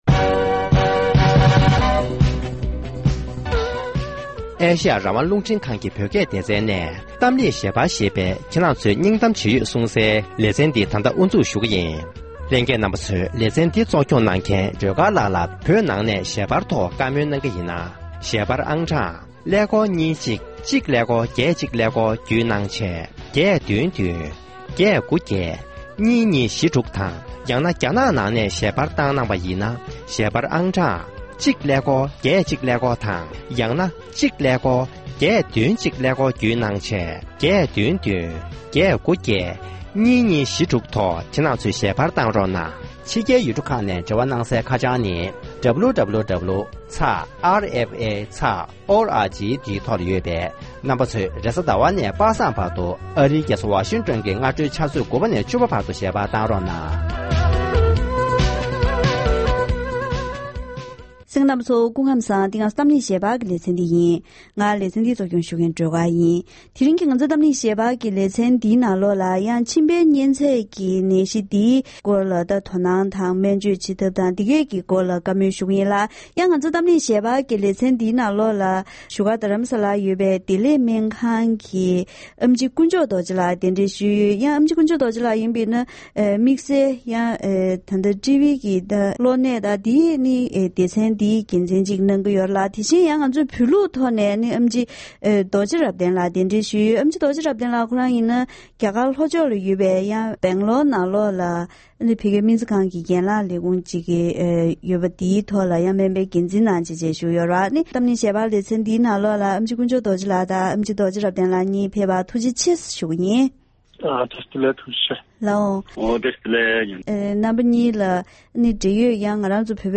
༄༅༎དེ་རིང་གི་གཏམ་གླེང་ཞལ་པར་ལེ་ཚན་ནང་མཆིན་པའི་གཉེན་ཚད་ཀྱི་ནད་གཞི་འགོས་པའི་བརྒྱུད་ལམ་དང་ནད་རྟགས། དེ་བཞིན་མཆིན་པའི་གཉེན་ཚད་ཀྱི་སྨན་བཅོས་བྱེད་ཐབས་སོགས་ཀྱི་ཐད་བོད་ལུགས་དང་ཕྱི་ལུགས་ཀྱི་སྨན་པར་བཀའ་མོལ་ཞུས་པ་ཞིག་གསན་རོགས་གནང་།།